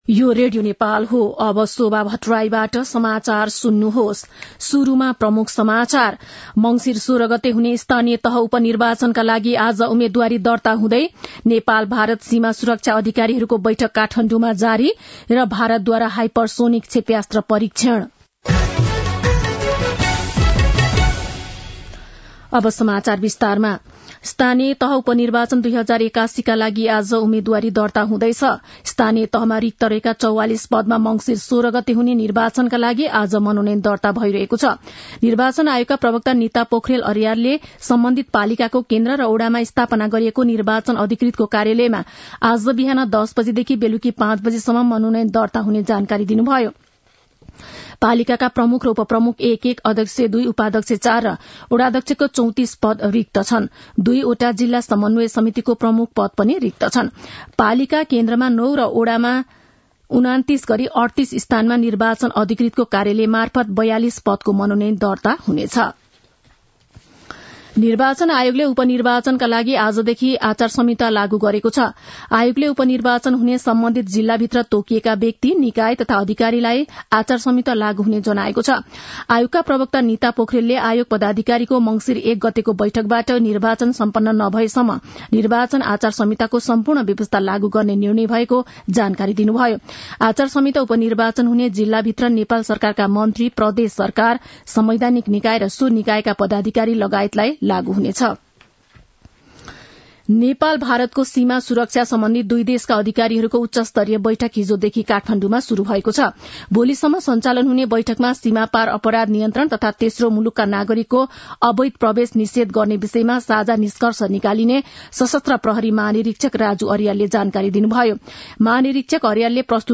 दिउँसो ३ बजेको नेपाली समाचार : ३ मंसिर , २०८१